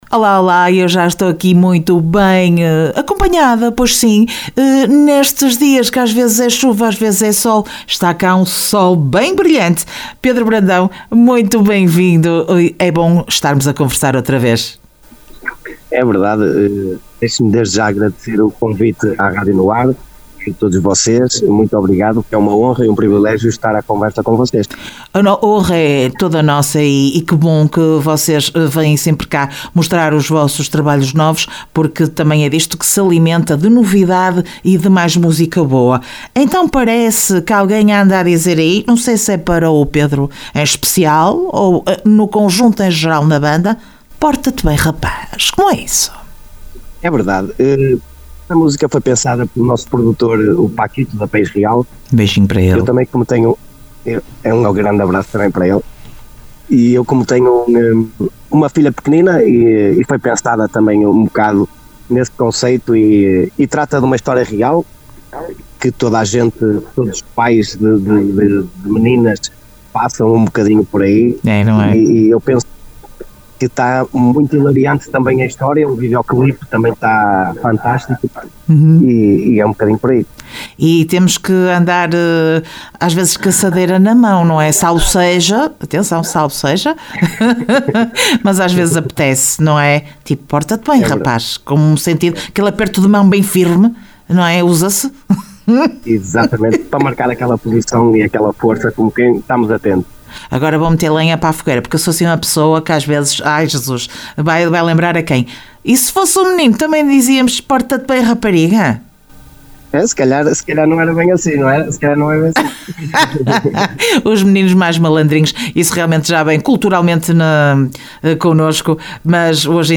Entrevista Banda Sol Brilhante dia 11 de Maio 2025.
ENTREVISTA-SOL-BRILHANTE-01.mp3